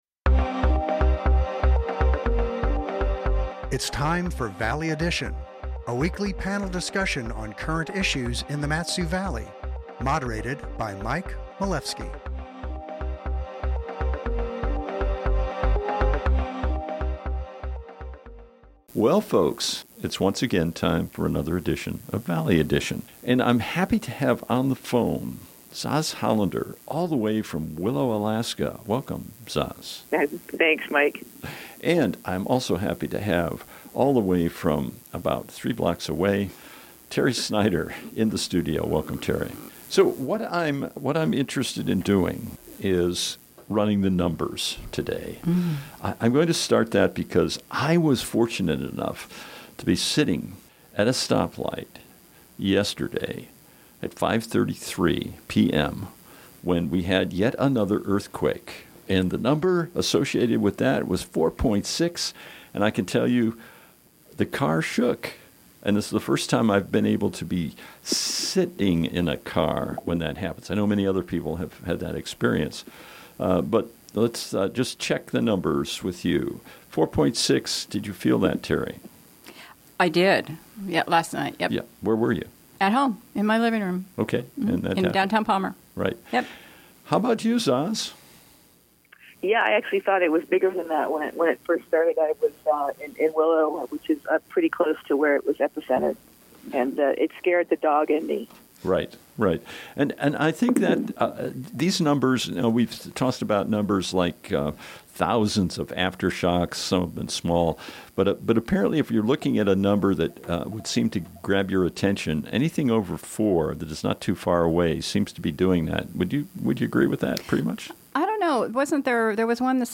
moderates a panel on current issues in the Valley